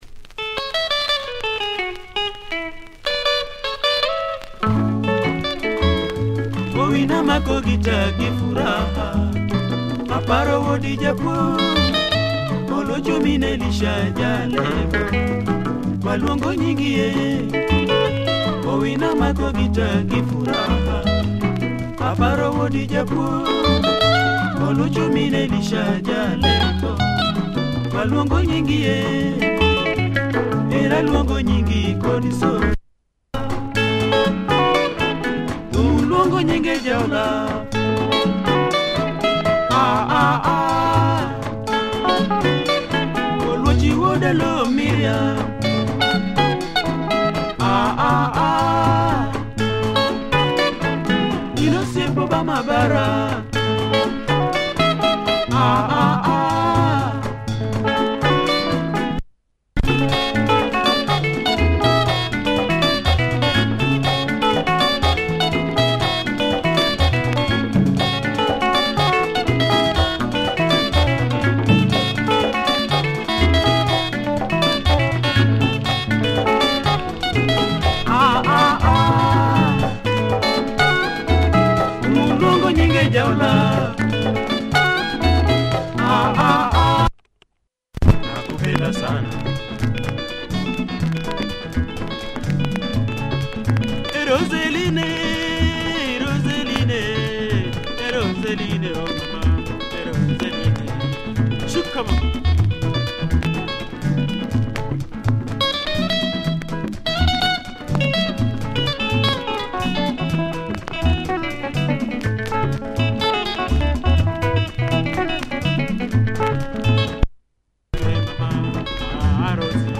Nice luo benga, check audio for both songs! https